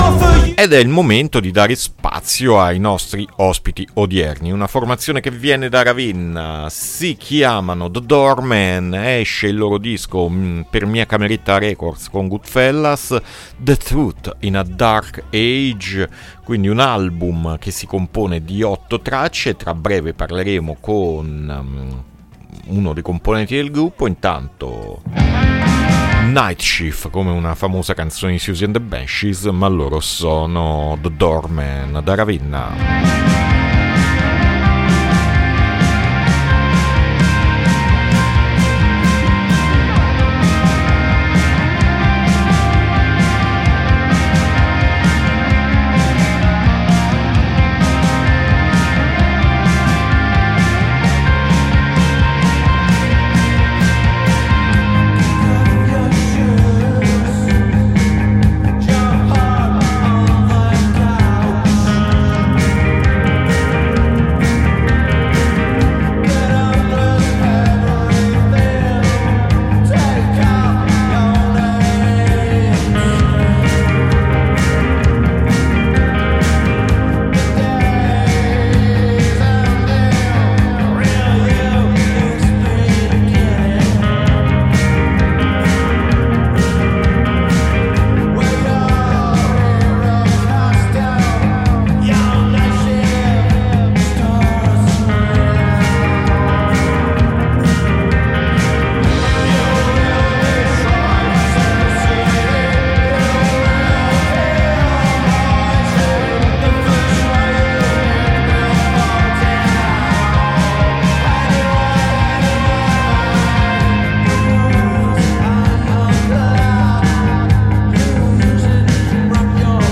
INTERVISTA THE DOORMEN A MERCOLEDI' MORNING 7-6-2023